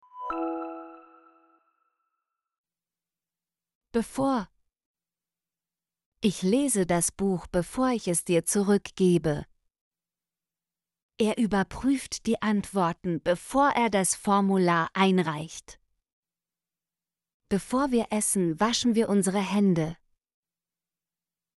bevor - Example Sentences & Pronunciation, German Frequency List